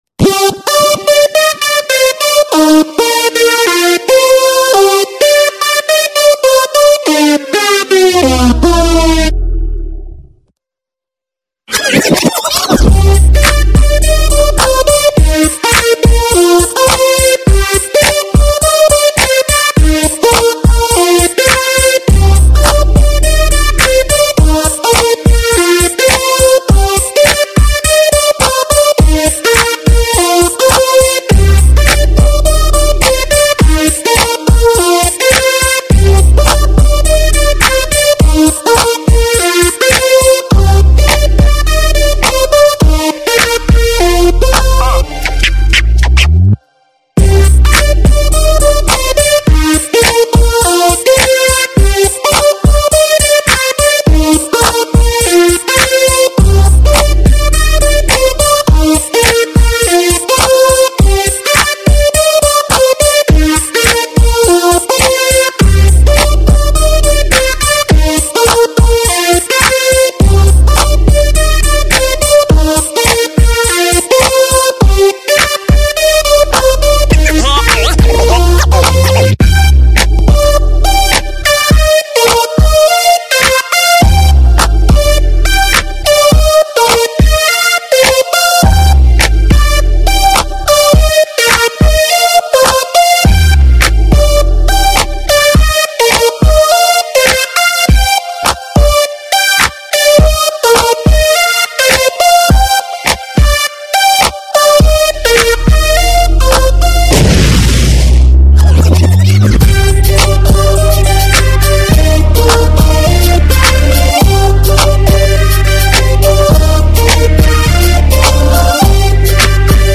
Категория: Клубняк